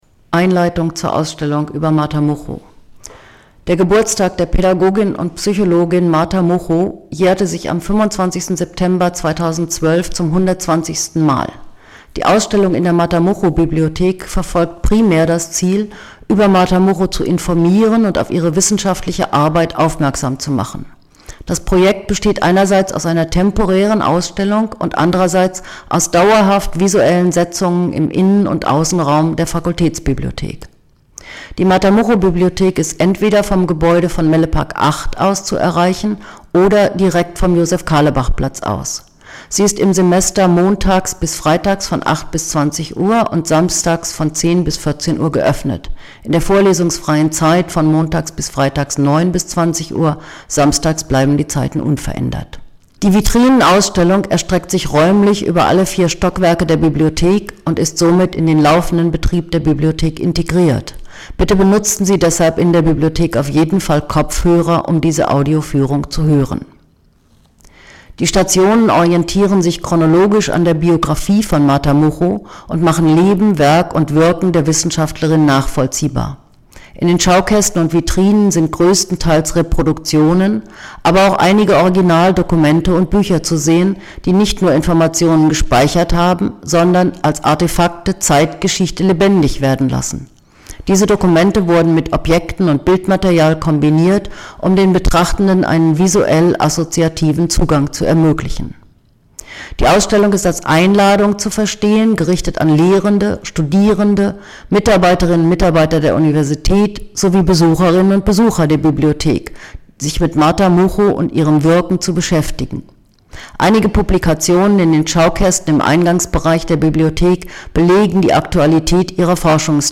Audioguide: Intro)